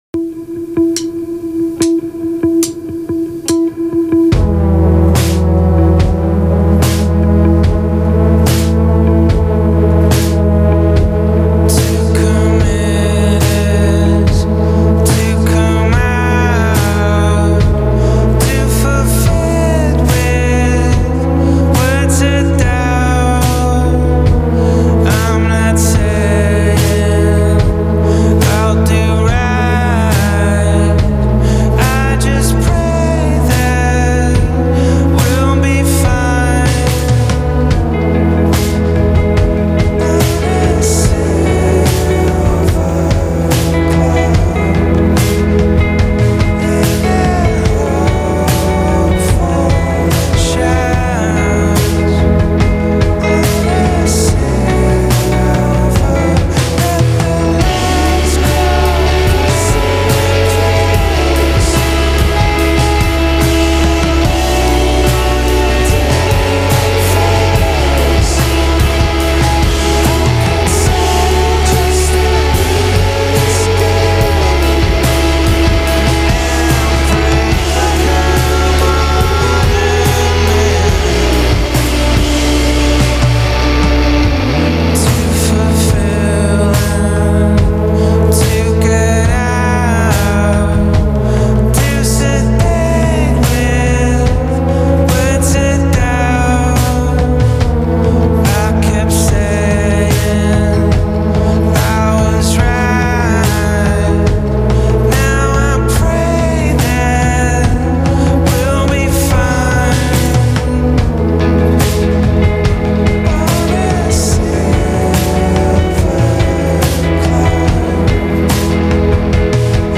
Indie from The Netherlands this week.